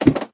ladder3.ogg